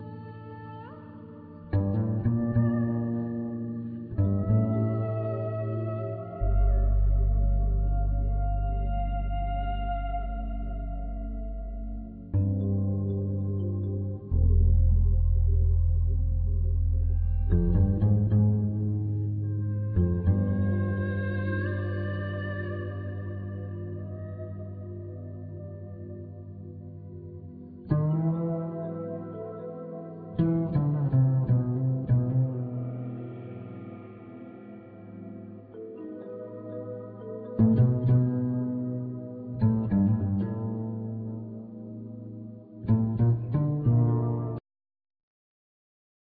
trumpet, flugelhorn
acoustic bass
tenor and soprano saxophone
drums and percussion
synthesizers, piano